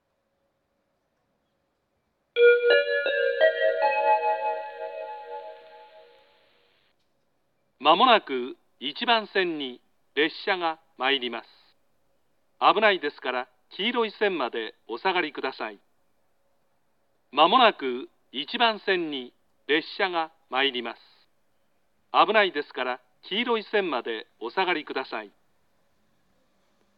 接近放送
発車メロディー(Capuccino)   過去に武蔵野線で使われていた声質の放送です。